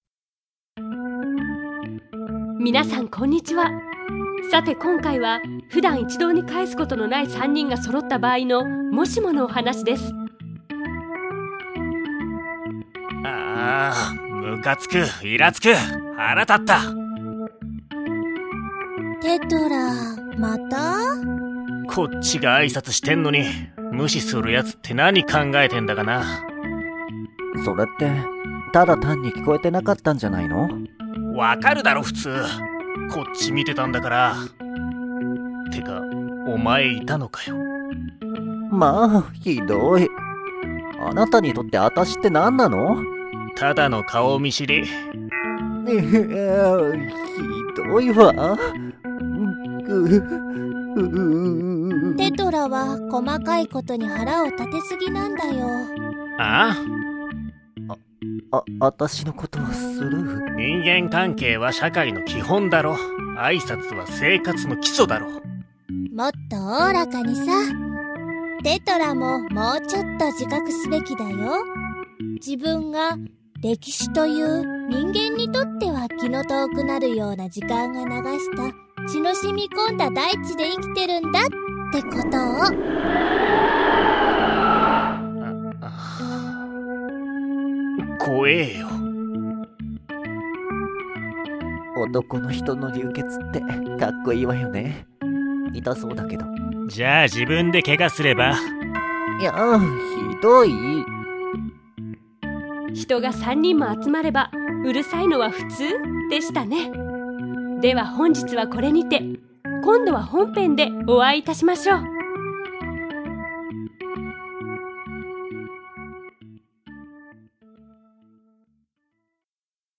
lemma - ボイスドラマ
BGM拝借
SE拝借